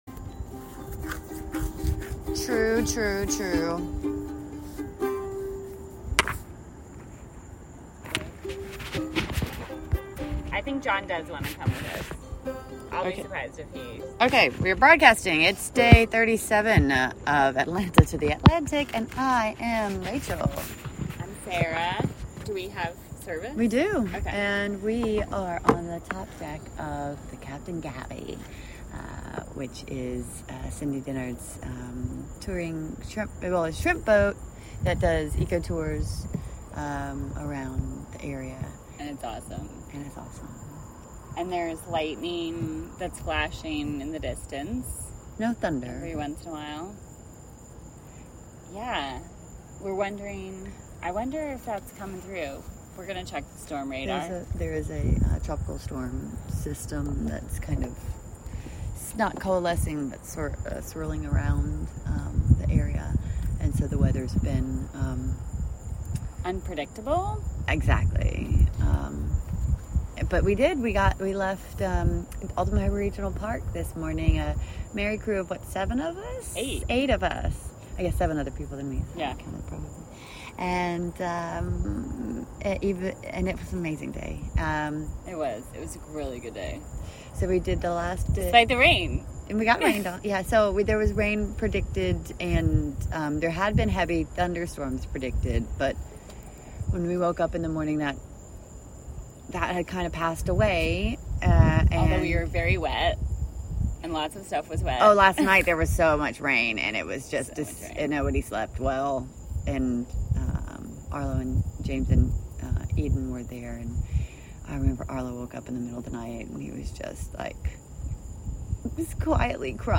Live from Flux Projects: Atlanta to the Atlantic, day 37 (Audio)